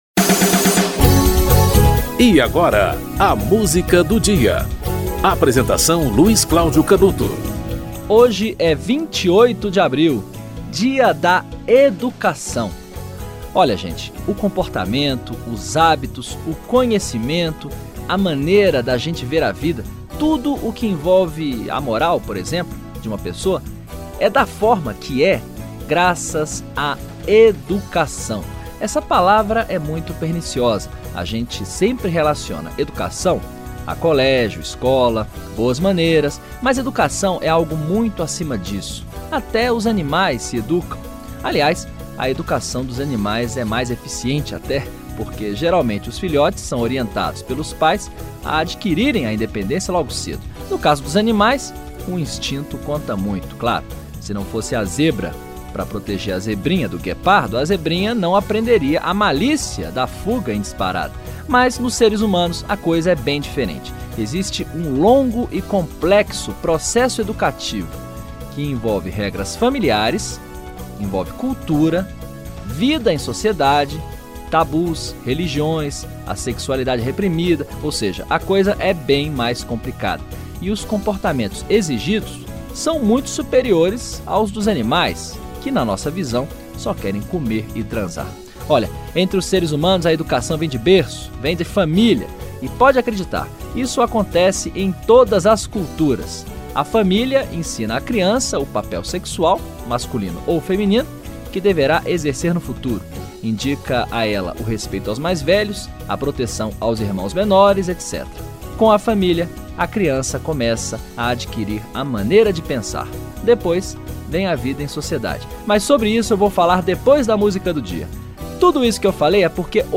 Chico Buarque - O Caderno (Toquinho e Mutinho)